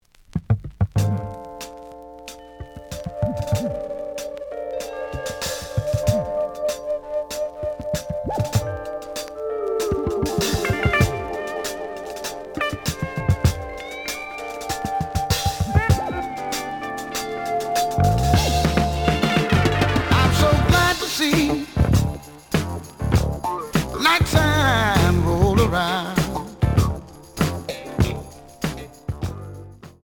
(Stereo)
試聴は実際のレコードから録音しています。
●Genre: Funk, 70's Funk